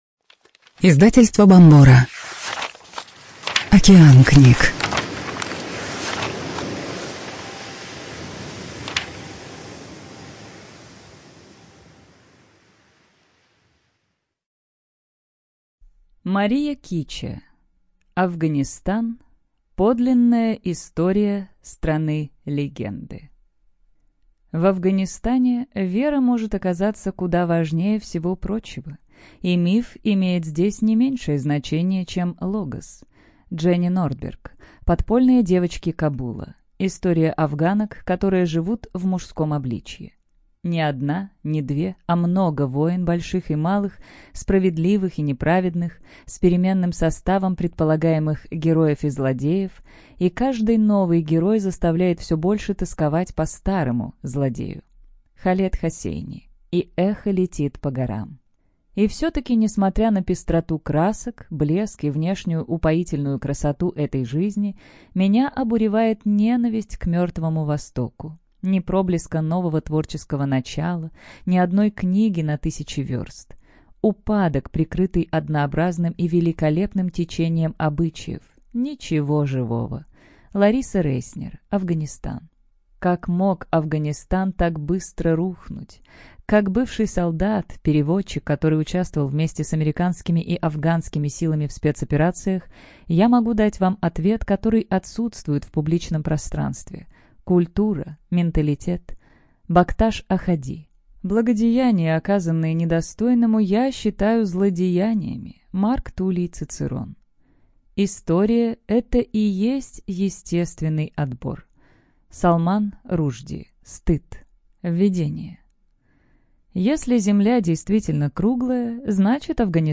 ЖанрНаучно-популярная литература
Студия звукозаписиБомбора